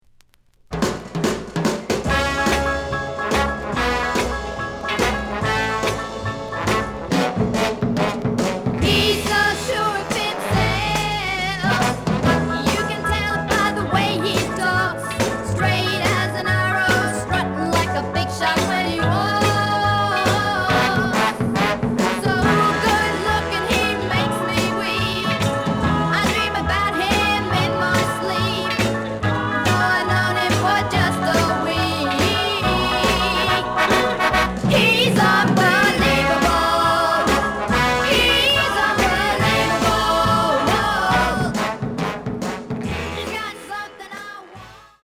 The listen sample is recorded from the actual item.
●Genre: Rock / Pop